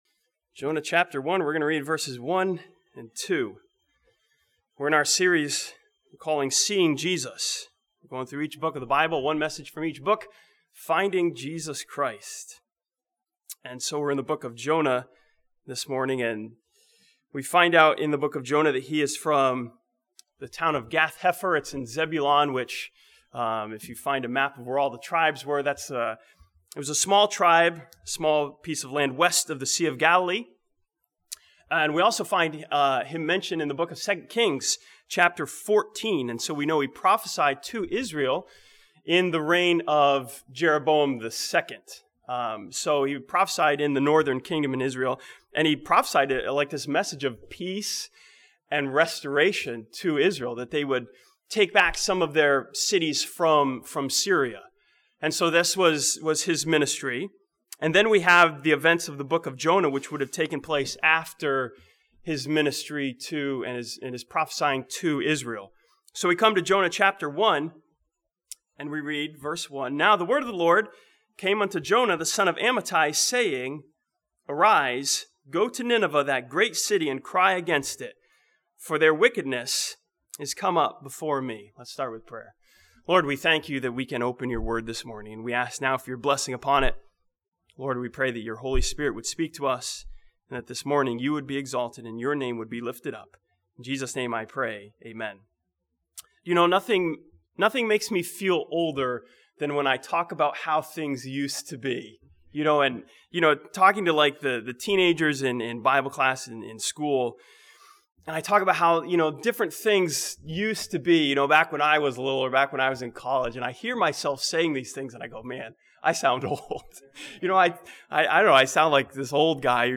This sermon from Jonah chapter ones sees Jesus as God's missionary from the story of Jonah the missionary to Nineveh.